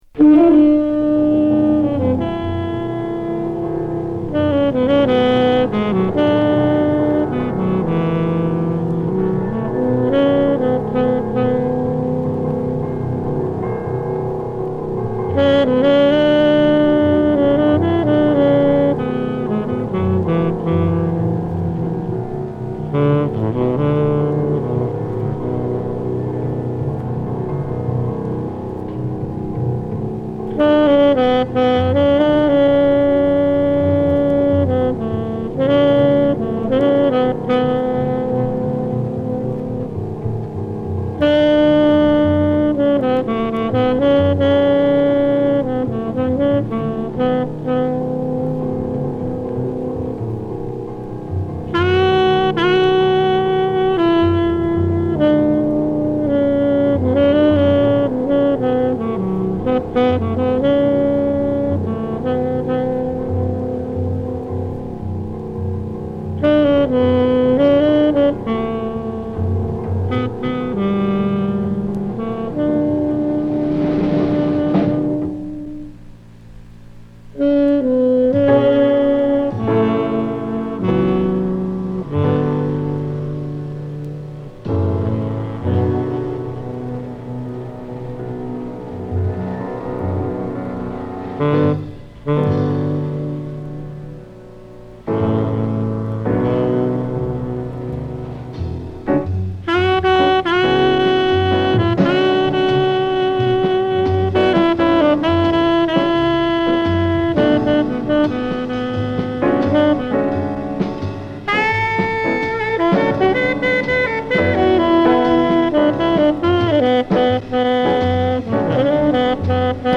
64年と65年に行ったライブを録音した1枚